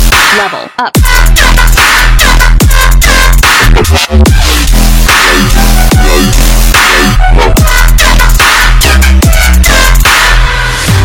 You've Level Up In A Video Game Sound Effect Download: Instant Soundboard Button